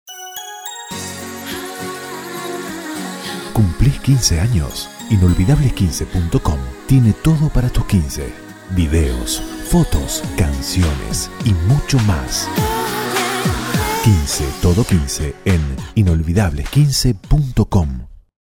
¿Todavía no escuchaste la publicidad de Inolvidables15 en Radio Disney ?